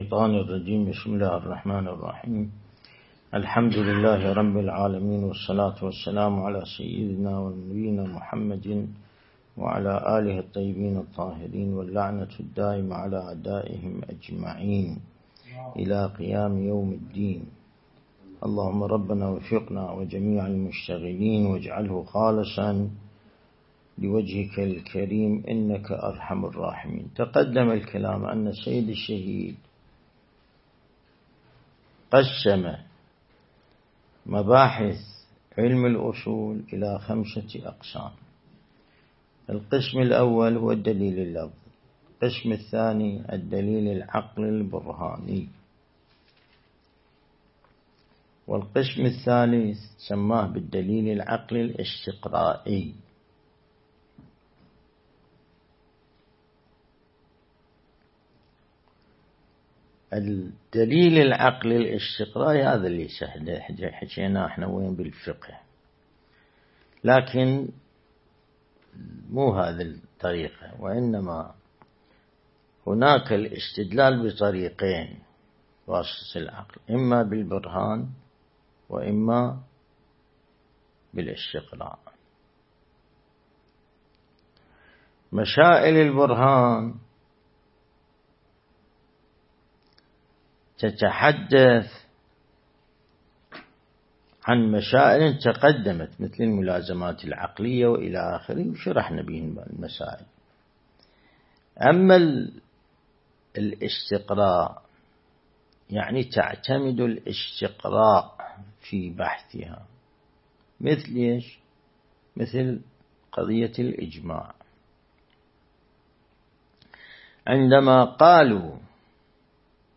درس البحث الخارج الأصول
النجف الأشرف